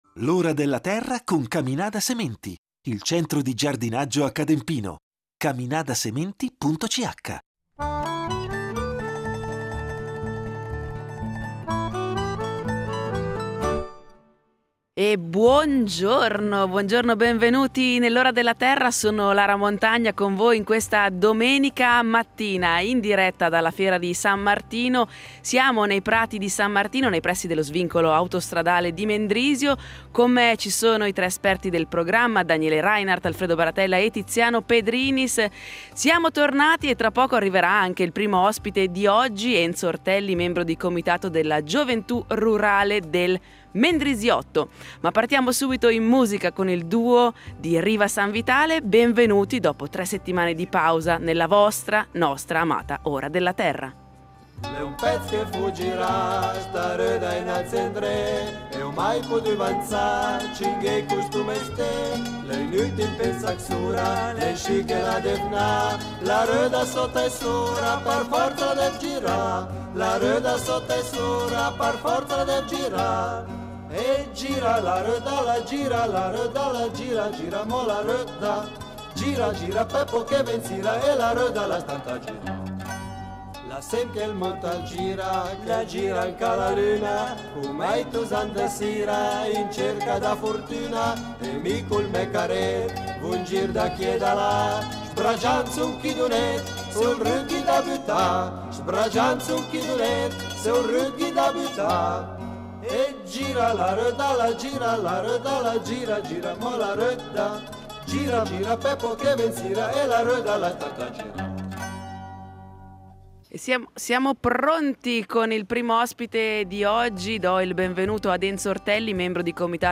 L’Ora della Terra in diretta dalla Fiera di San Martino , la fiera paesana del Borgo.